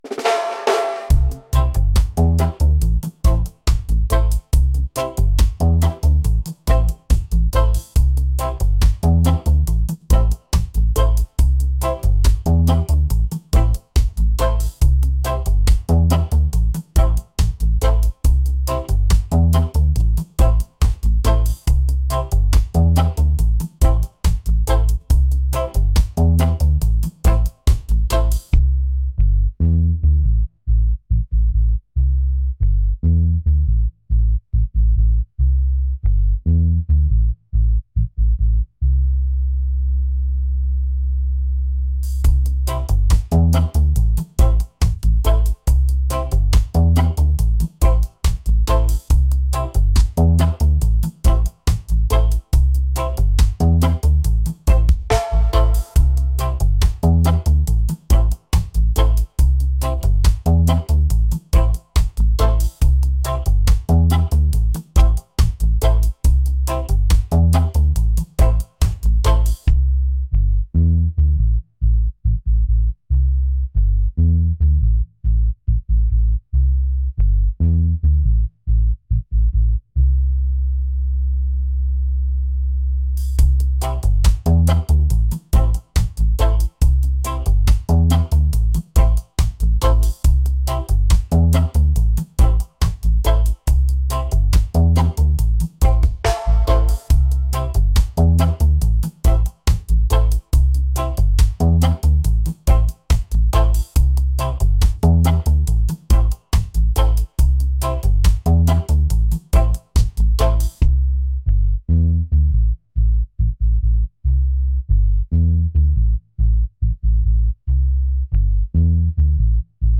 reggae | laid-back | groovy